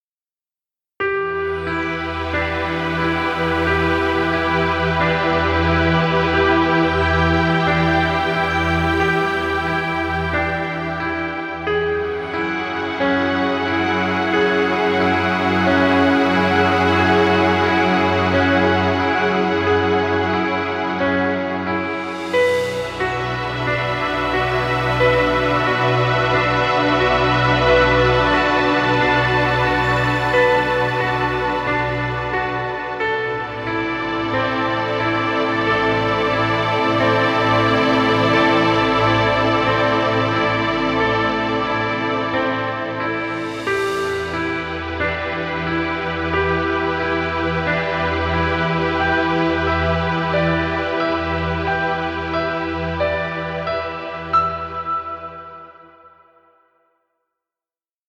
calm relaxing track. Ambient orchestra with a space mood.
Relaxing ambient music. Background Music Royalty Free.